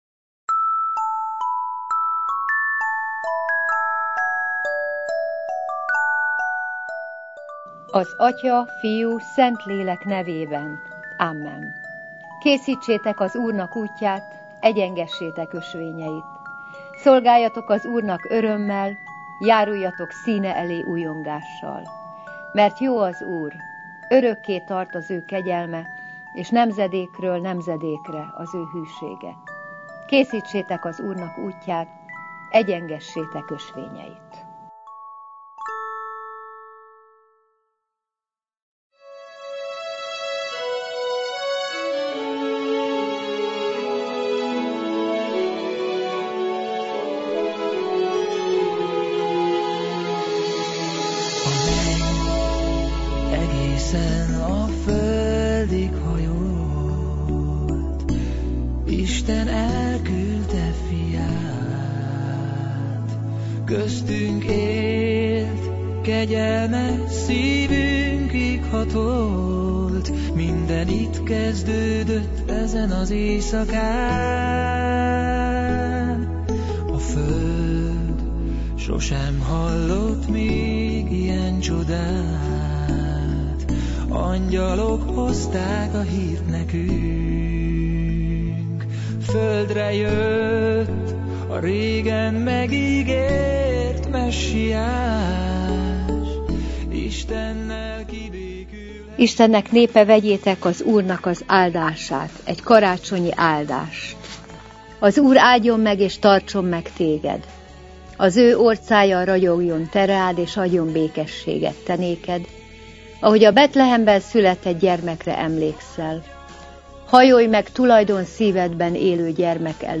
Vallásos műsor Evangélikus felekezet Evangélikus felekezet 2018. december 23.-i adása By Bocskai Rádió - 2018. december 23.